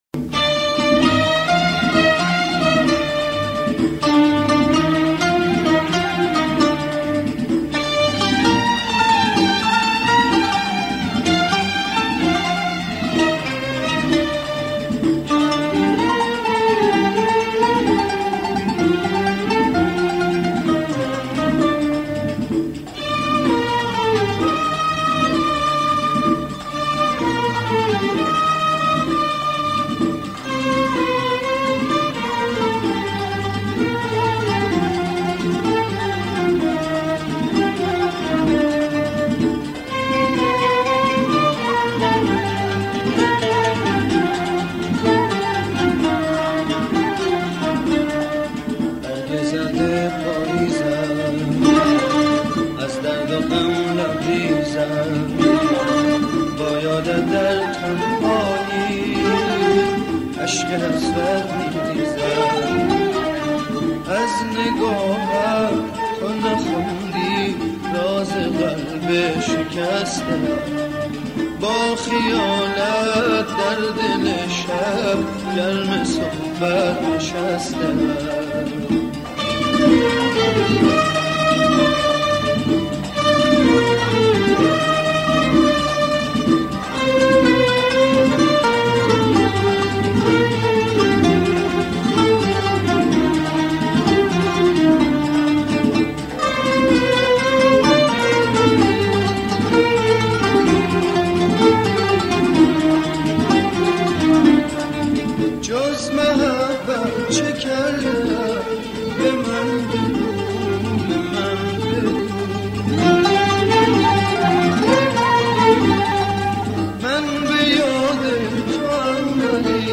آهنگ قدیمی
غمگین